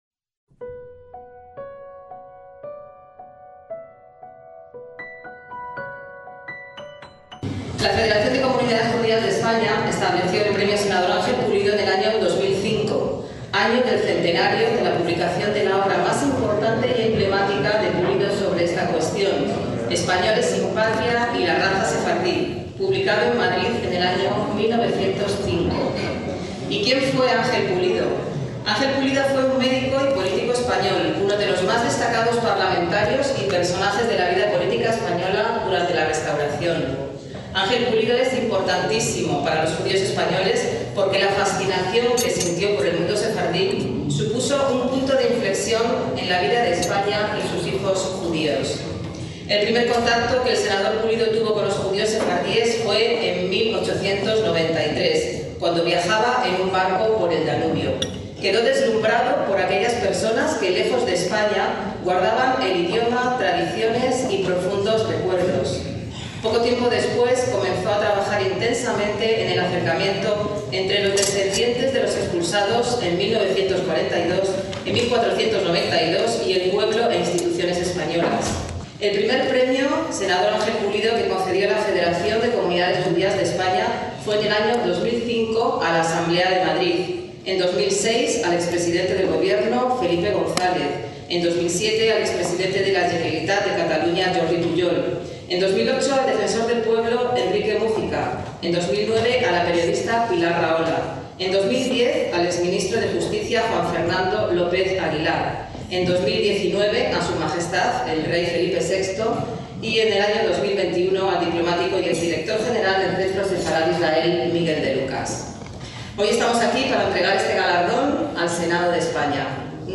ACTOS EN DIRECTO - El pasado 22 de febrero de 2024 tuvo lugar en Madrid el acto de entrega del Premio Senador Ángel Pulido 2022 al Senado de España.